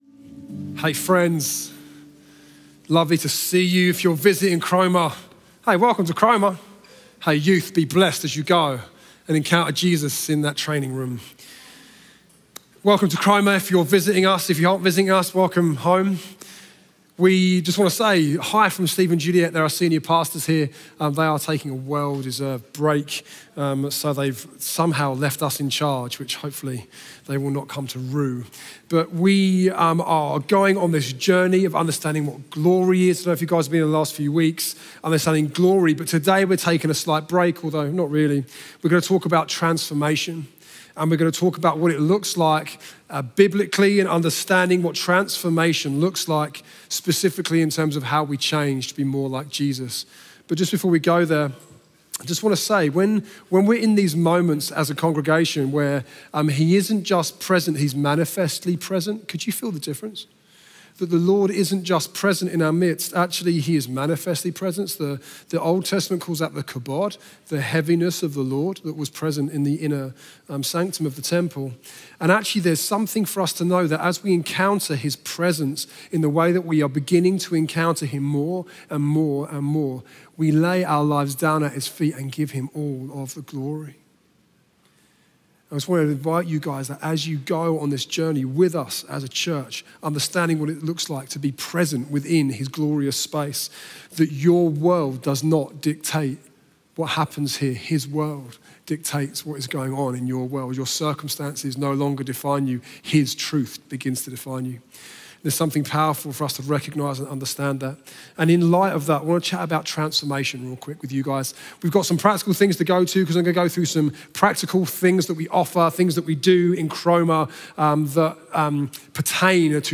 Chroma Church - Sunday Sermon Transformed by Jesus Oct 06 2023 | 00:34:31 1x 00:00 / 00:34:31 Subscribe Share RSS Feed Share Link Embed